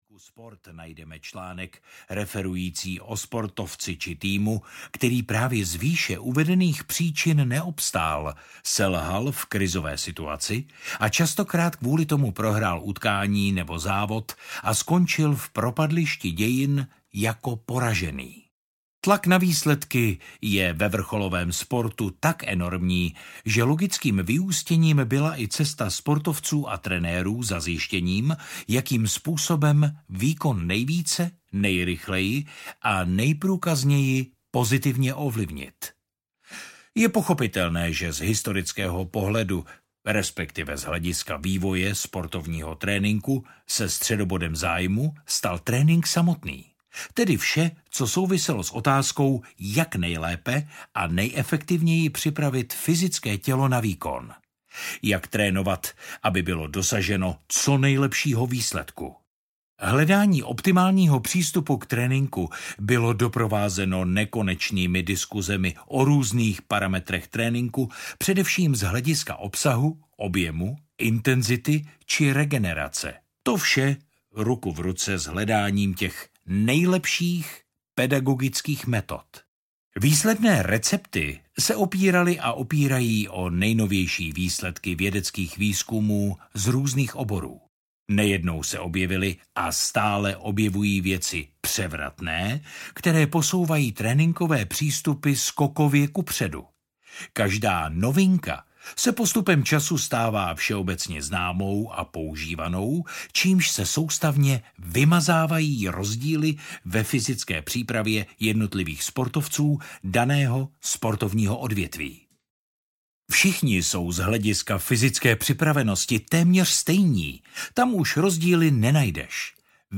Ukázka z knihy
• InterpretBohdan Tůma